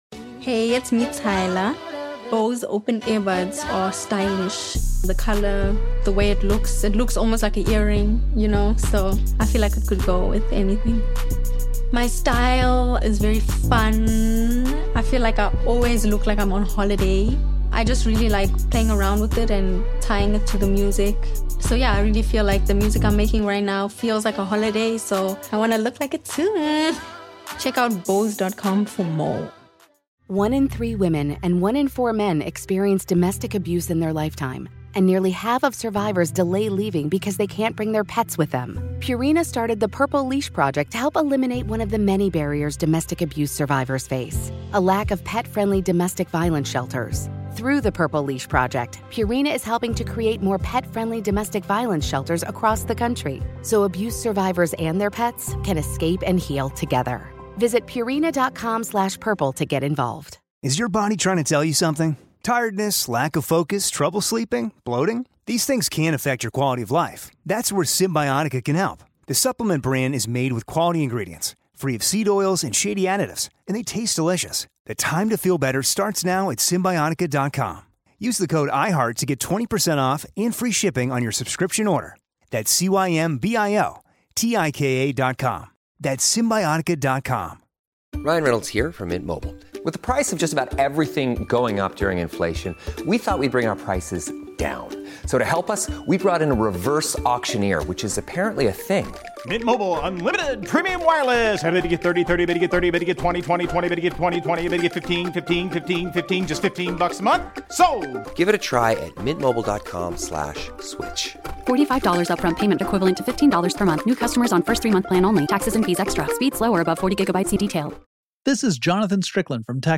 Ambrose passed away in 2002 but his epic storytelling accounts can now be heard here at Our American Stories thanks to those who run his estate. Our next story is the story of weapons used in WWII.